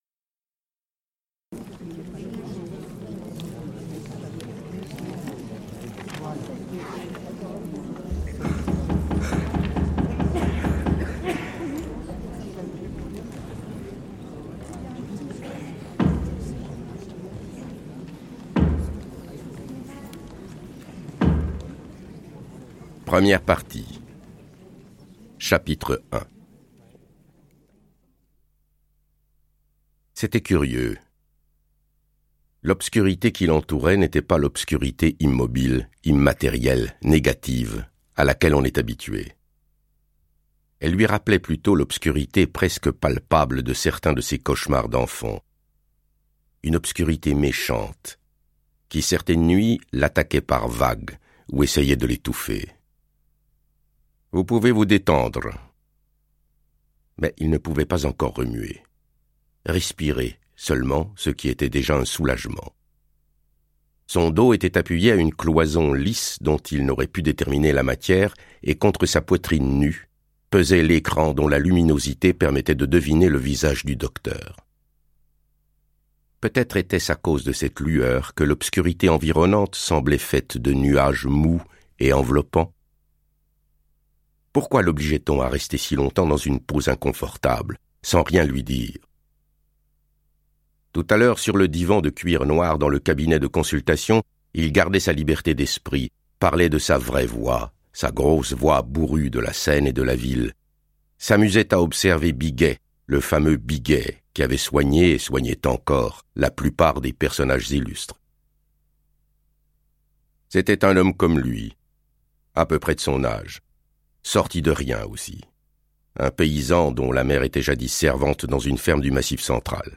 Extrait gratuit - Les Volets verts de Hervé Pierre, Georges Simenon